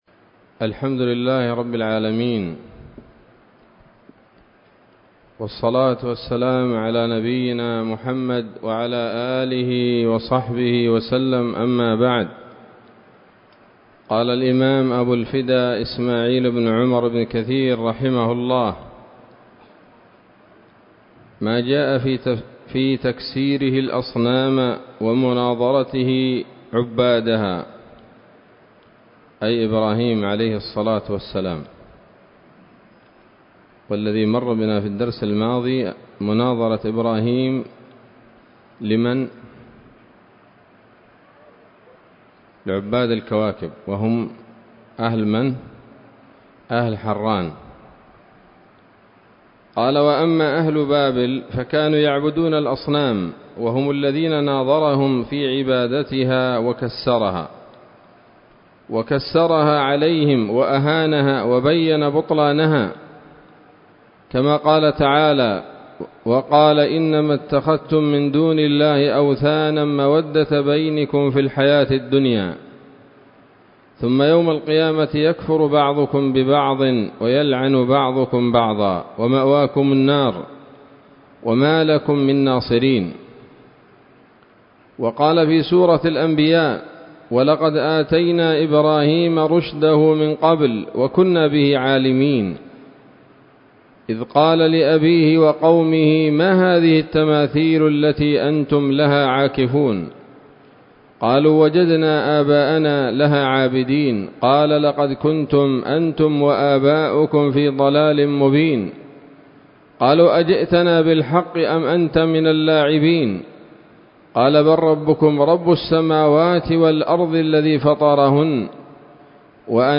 الدرس الثاني والأربعون من قصص الأنبياء لابن كثير رحمه الله تعالى